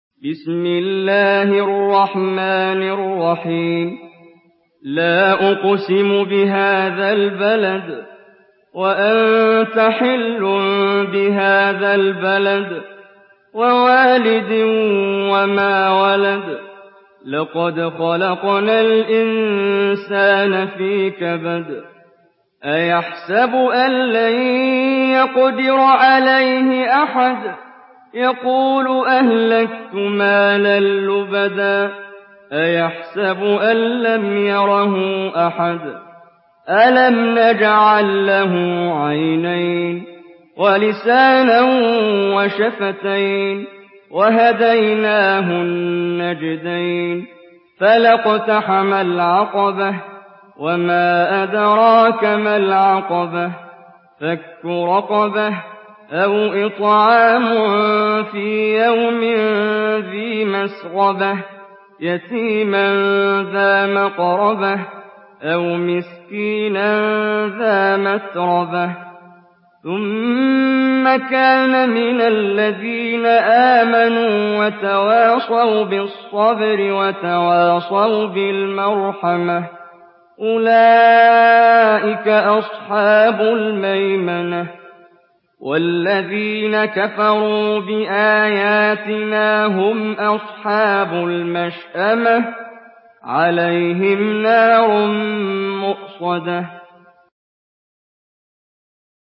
سورة البلد MP3 بصوت محمد جبريل برواية حفص
مرتل